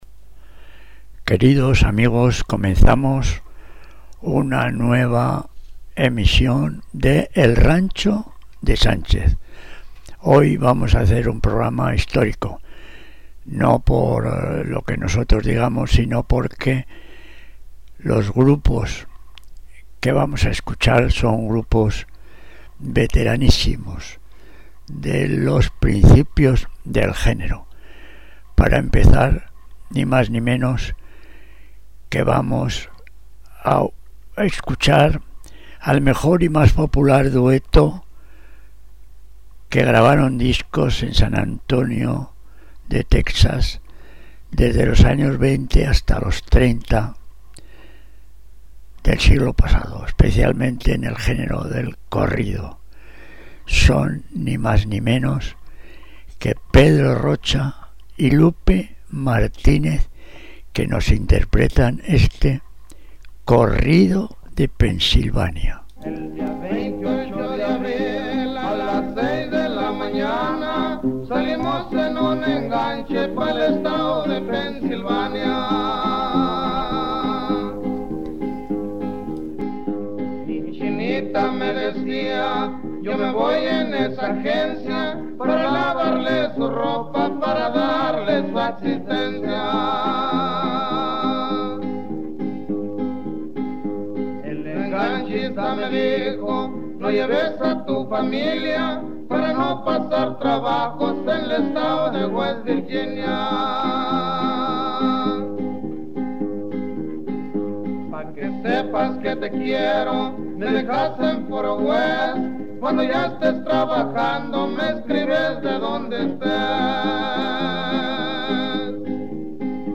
corriente Tex -mex